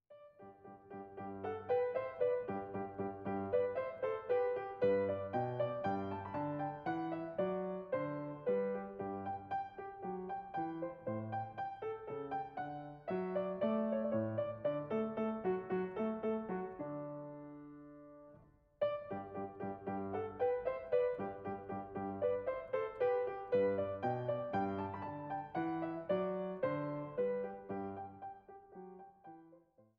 Mezzosopran, Orgel
Bariton, Orgel, Cembalo
Laute
Violoncello, Viola da gamba